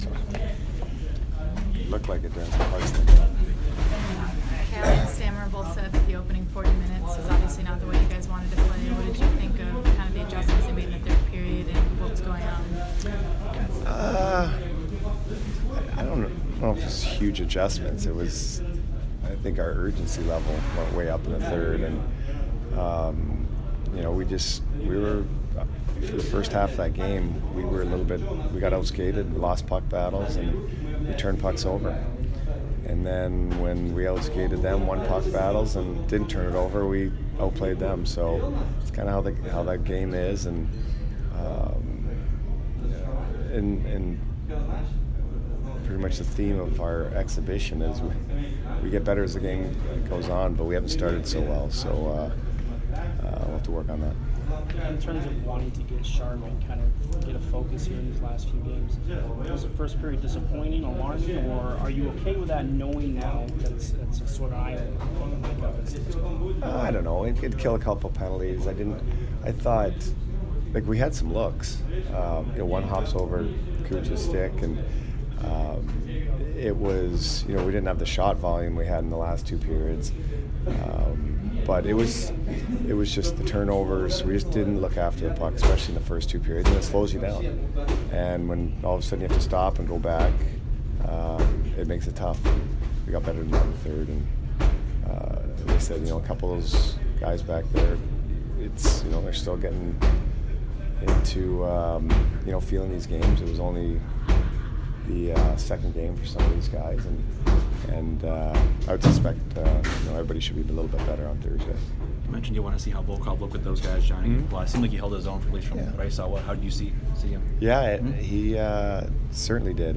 Cooper Post-Game 9/26 @ FLA